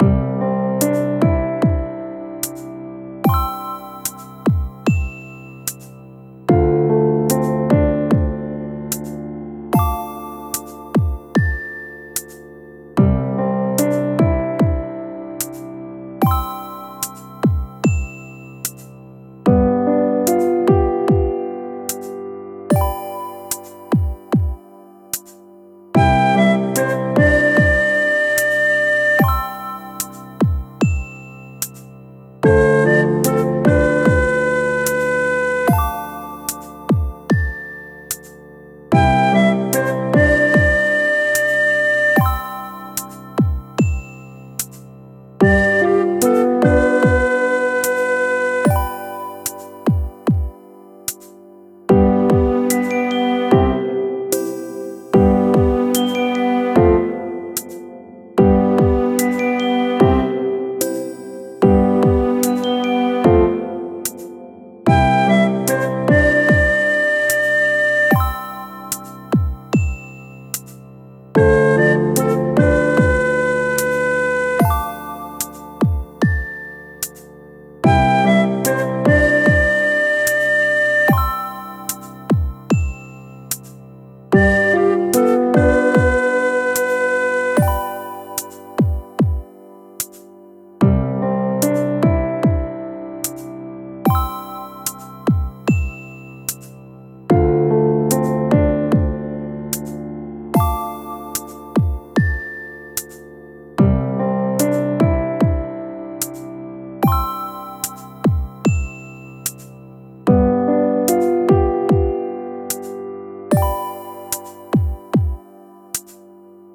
のんびりした雰囲気を持った、みんなが眠りについた後の様な、夜の日常BGMです。
ほのぼのした雰囲気のコンテンツをイメージしています。